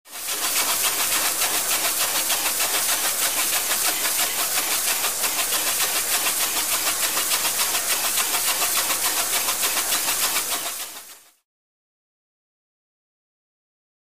Hose, Spray
Spraying Metal Car Grill In A Large Garage